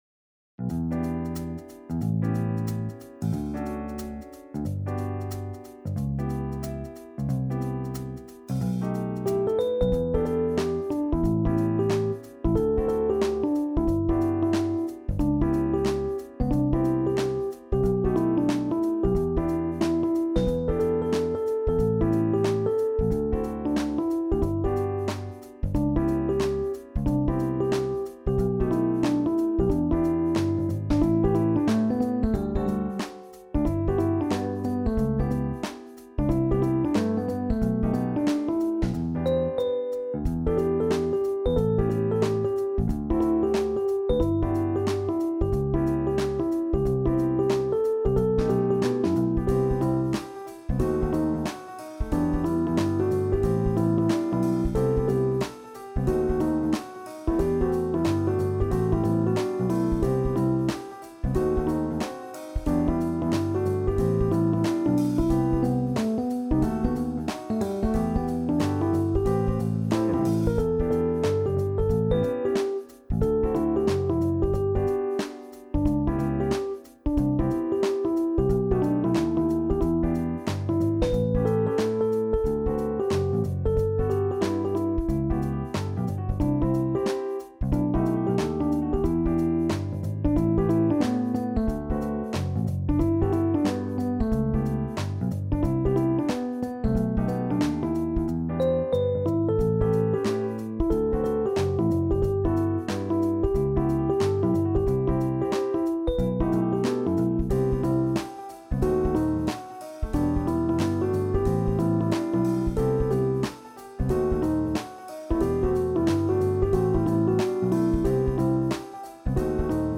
SSATB avec solo
súper funky